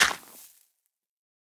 brushing_gravel_complete2.ogg